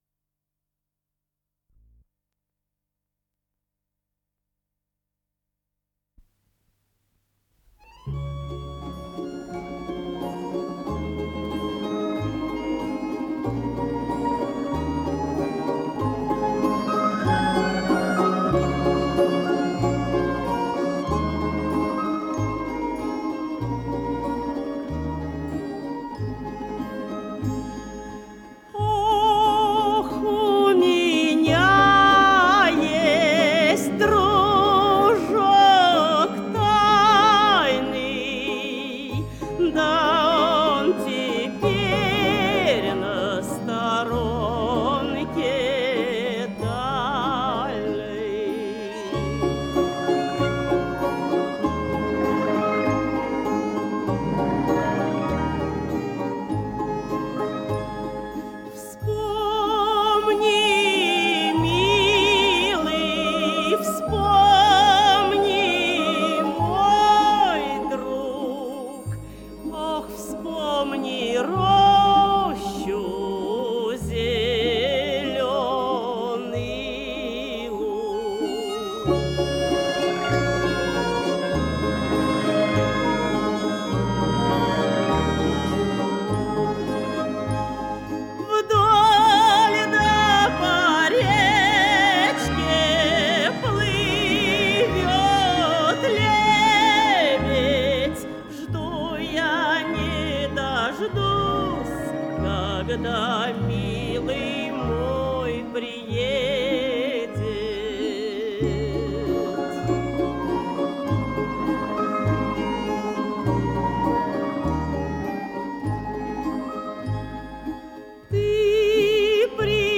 с профессиональной магнитной ленты
КомпозиторыРусская народная песня
ИсполнителиЛюдмила Зыкина - пение
АккомпаниментГосударственный республиканский русский народный ансамбль "Россия"
ВариантДубль стерео